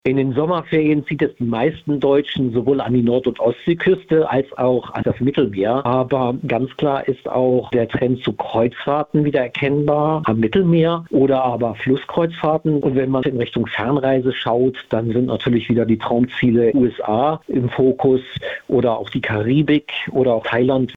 Interview: Reisetrends 2023 - PRIMATON